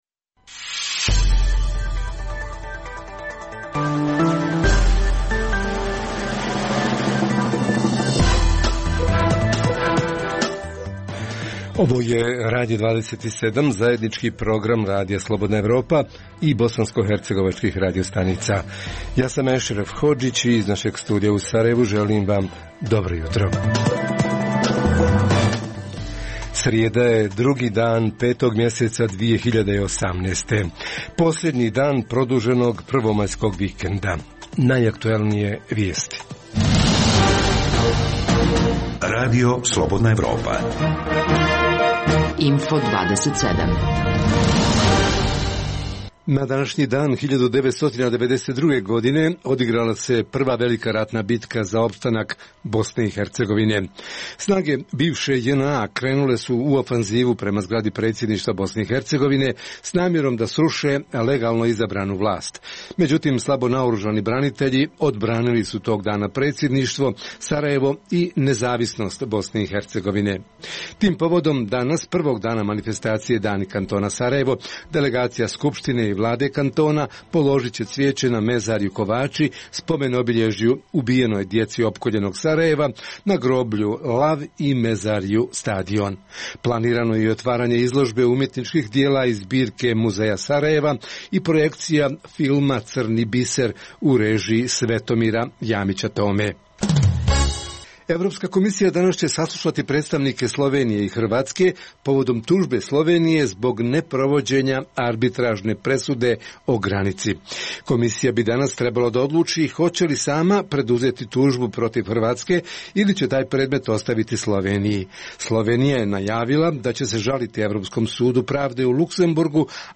Središnja tema jutra: Prvi maj, Međunarodni praznik rada, radnici i radnička prava – kako ih ostvaruju? O tome će naši reporteri iz Zenice, Zvornika, Olova, Konjica i Tuzle.